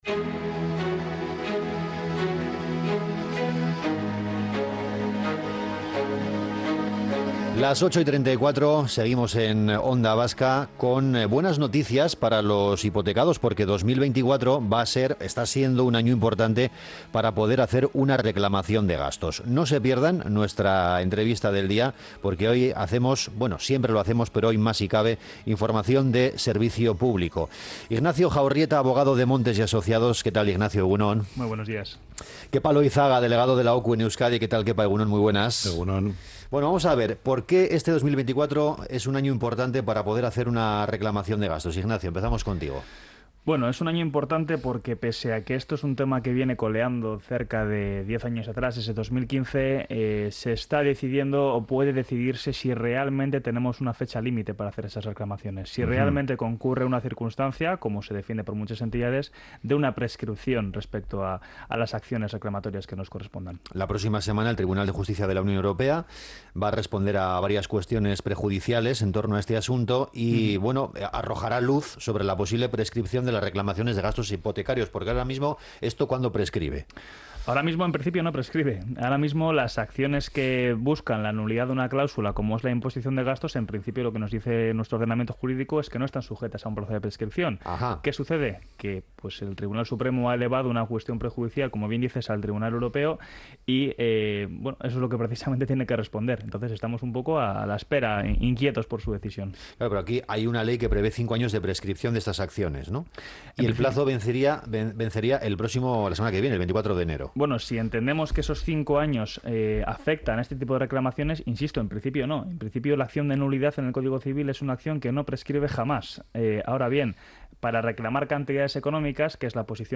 Onda Vasca Bizkaia en directo
Morning show conectado a la calle y omnipresente en la red.